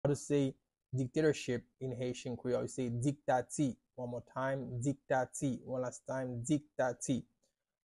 “Dictatorship” in Haitian Creole – “Diktati” pronunciation by a native Haitian tutor
“Diktati” Pronunciation in Haitian Creole by a native Haitian can be heard in the audio here or in the video below:
How-to-say-Dictatorship-in-Haitian-Creole-–-Diktati-by-a-native-Haitian-tutor.mp3